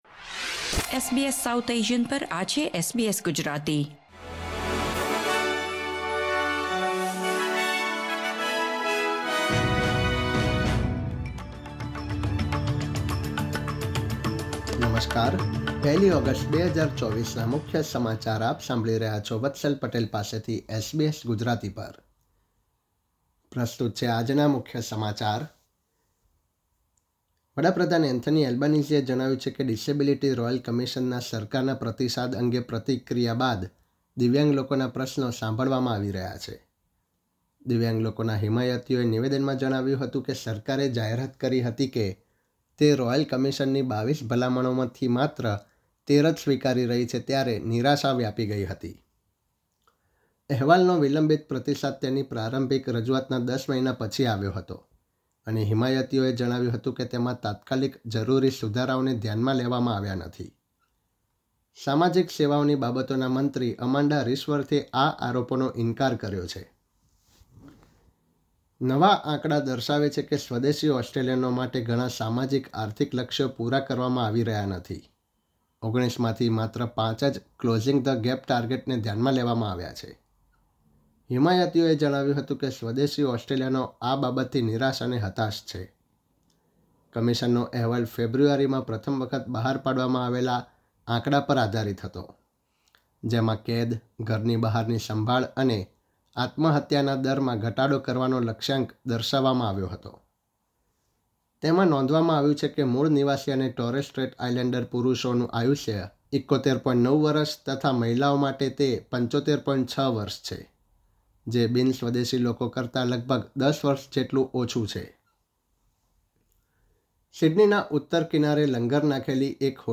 SBS Gujarati News Bulletin 1 August 2024